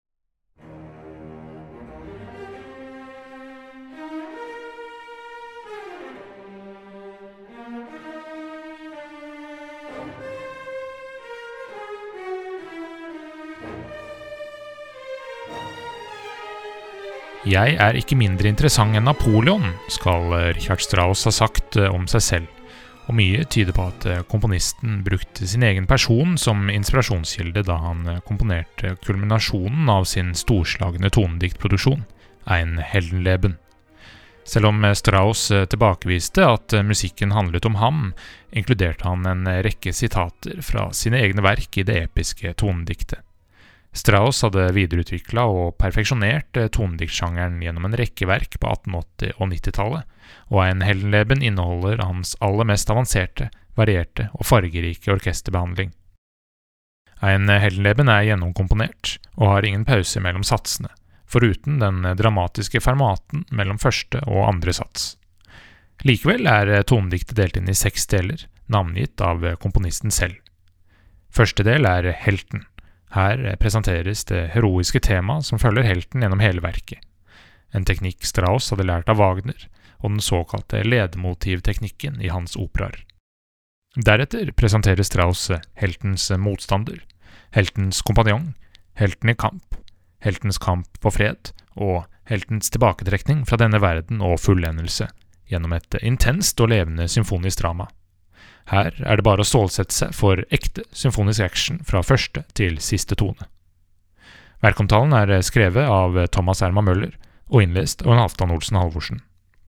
VERKOMTALE-Richard-Strauss-Ein-Heldenleben.mp3